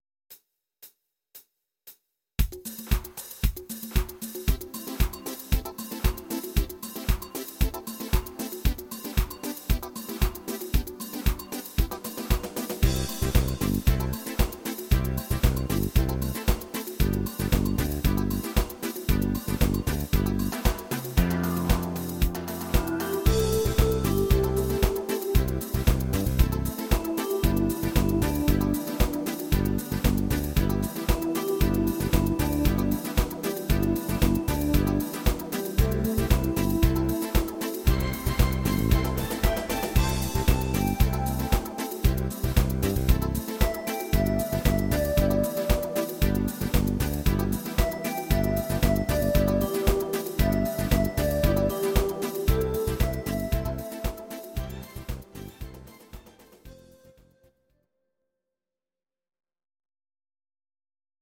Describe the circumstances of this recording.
Audio Recordings based on Midi-files